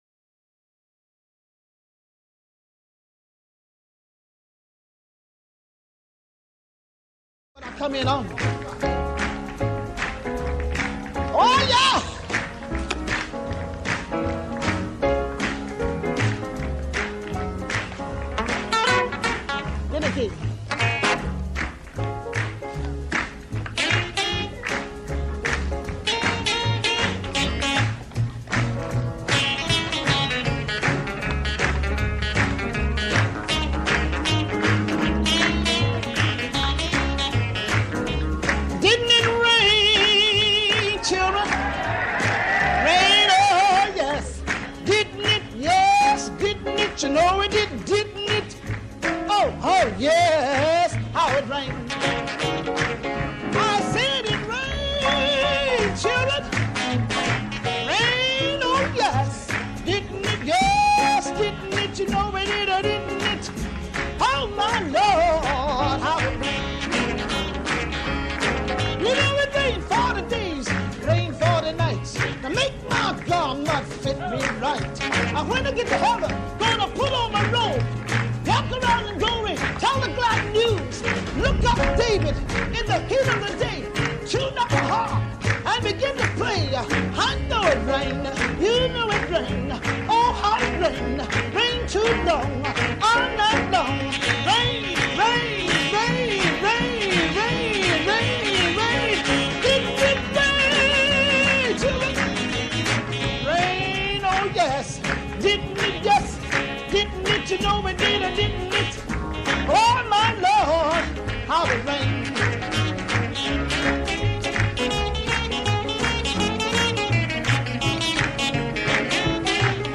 ファンキーゴスペルの姉御とか称されていました。